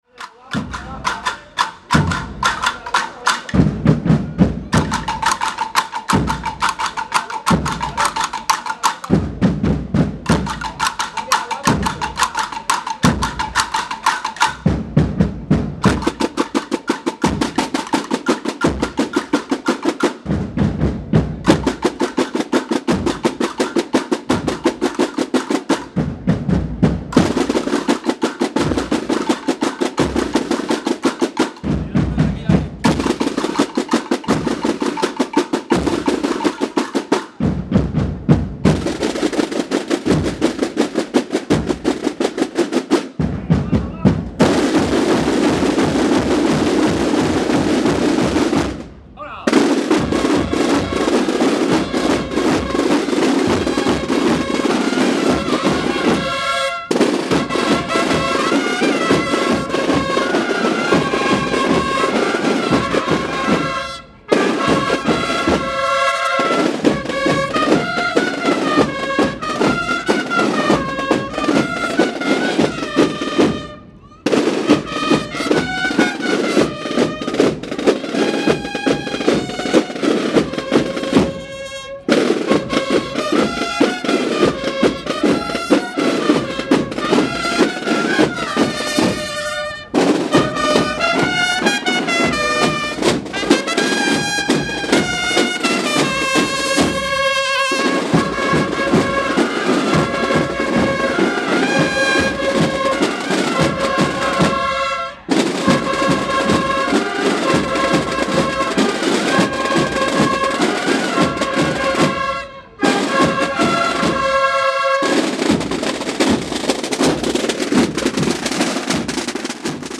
Procesión Santa María Cleofé 2016
A las 19:30 tuvo lugar una Procesión Extraordinaria con la imagen de Santa María Cleofé en andas y acompañados de la Banda de Cornetas y Tambores de esta Hermandad.